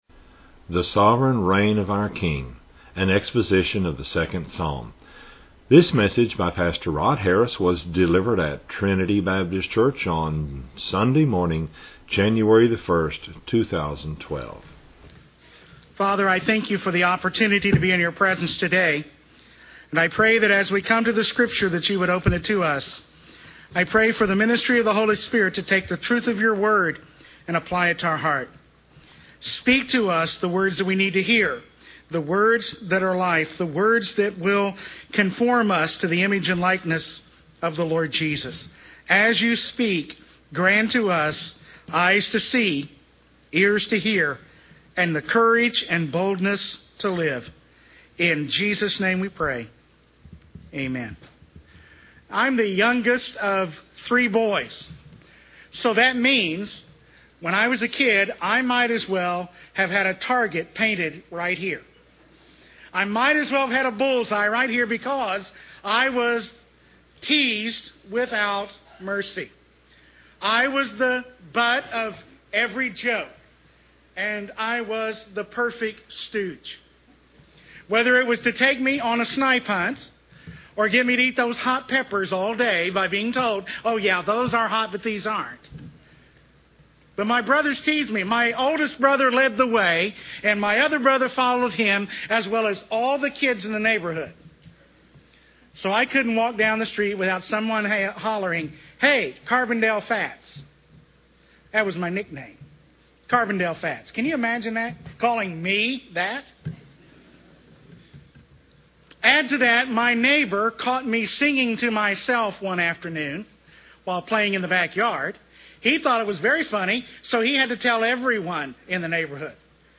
An exposition of Psalm 2.